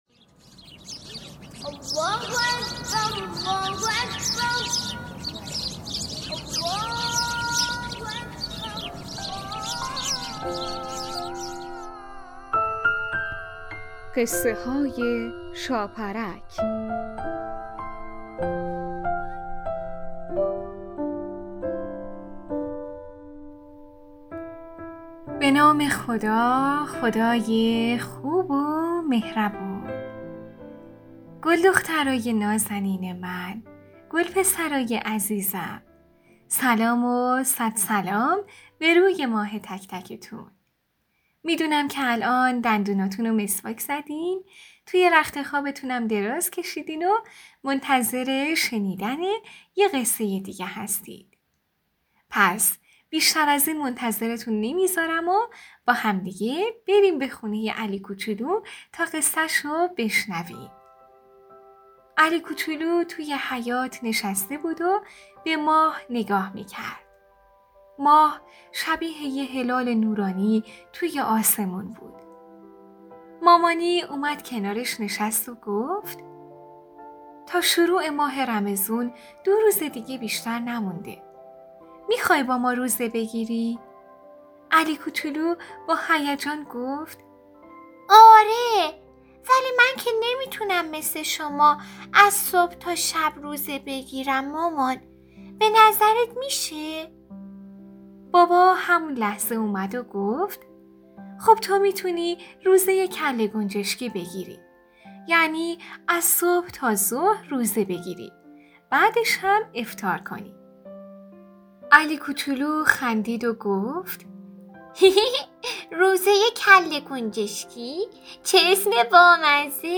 قسمت صد و شصت و چهارم برنامه رادیویی قصه های شاپرک با نام علی و روزه‌های کله‌گنجشکی داستان کودکانه با موضوعیت نمازی